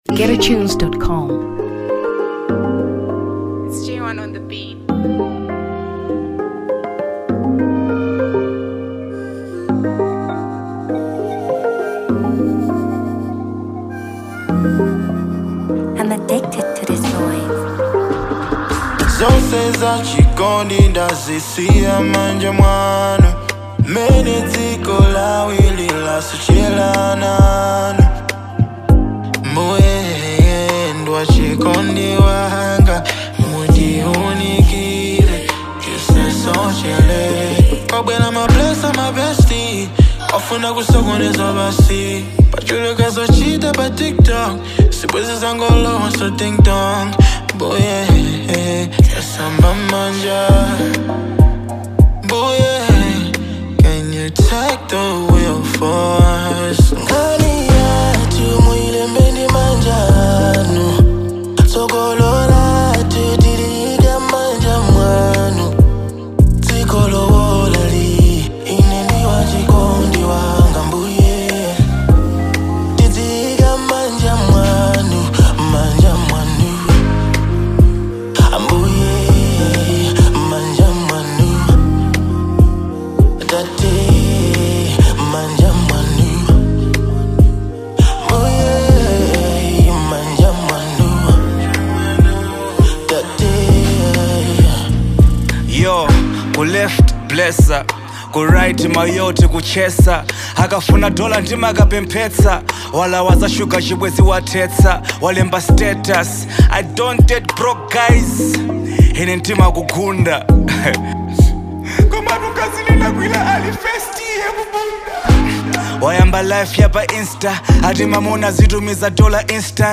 Gospel 2023 Malawi